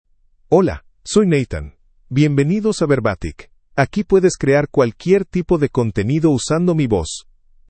Nathan — Male Spanish (United States) AI Voice | TTS, Voice Cloning & Video | Verbatik AI
Nathan is a male AI voice for Spanish (United States).
Voice sample
Male
Nathan delivers clear pronunciation with authentic United States Spanish intonation, making your content sound professionally produced.